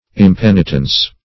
Impenitence \Im*pen"i*tence\, n. [L. impenitentia: cf. F.